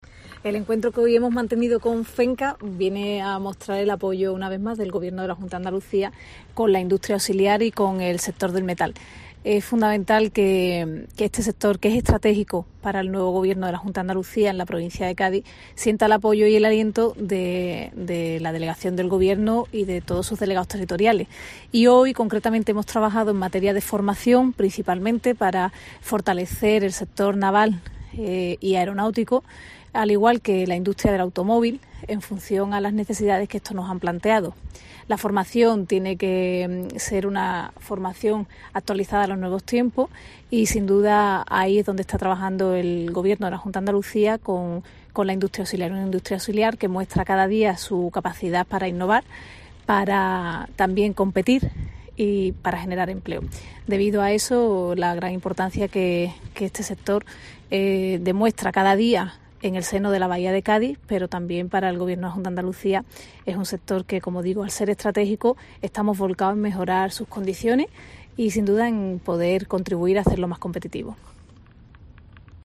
Ana Mestre, delegada de la Junta de Andalucía en Cádiz, sobre la industria de la Bahía de Cádiz